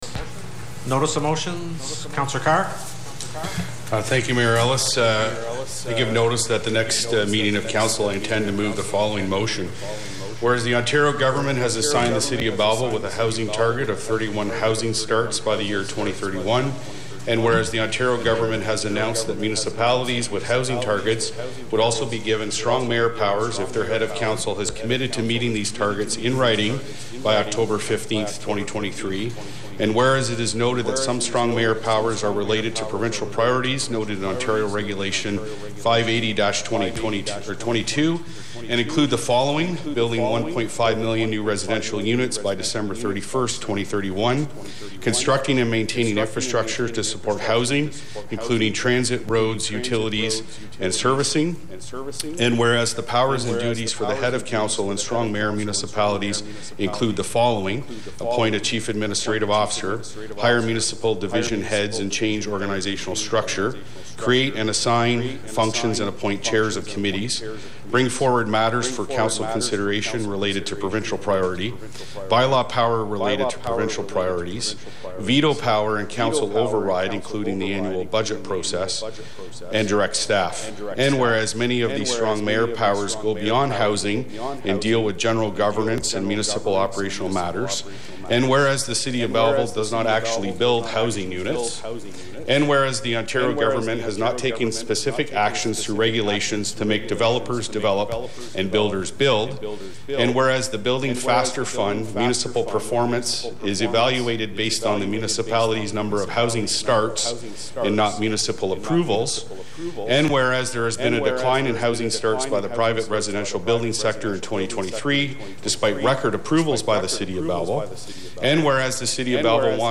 Councillor Paul Carr stood to make a notice of motion at Monday’s meeting, a motion that took about four minutes to read.
Below is audio (note: there is a faint echo) of Councillor Carr’s Notice of Motion.